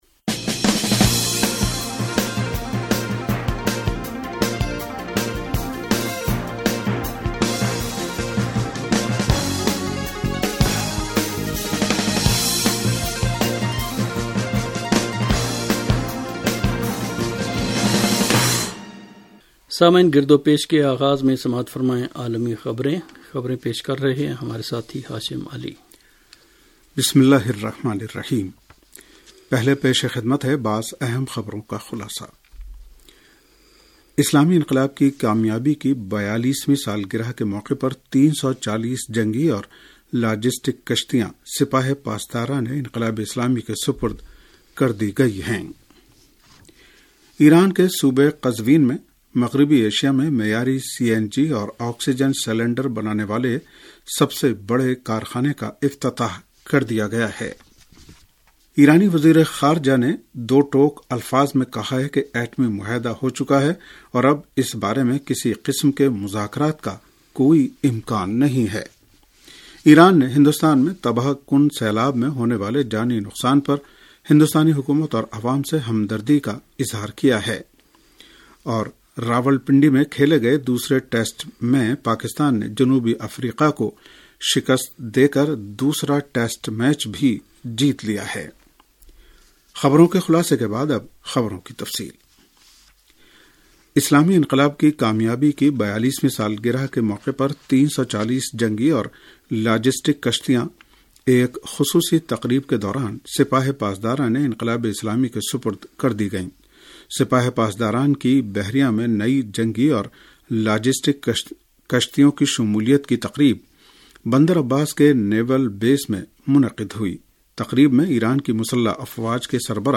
ریڈیو تہران کا سیاسی پروگرام گرد و پیش